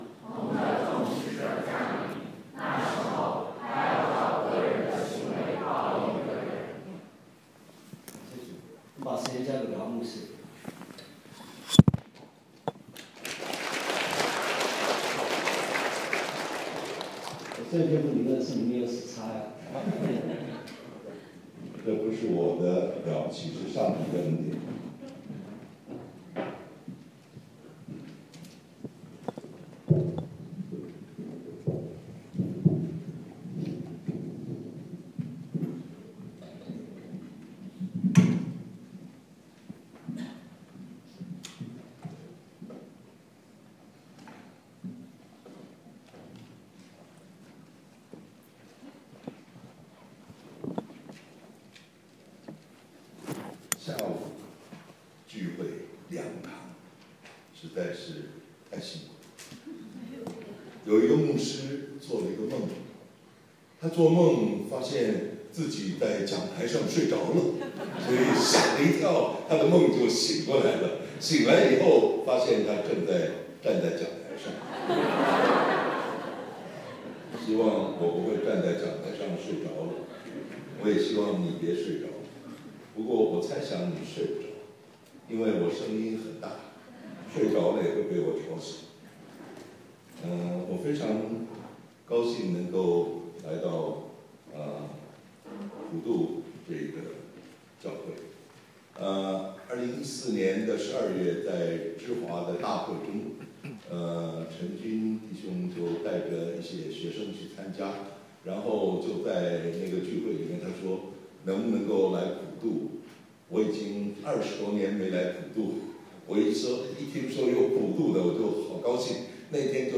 2017退修会录音5：实用个人布道—常胜兵法（上）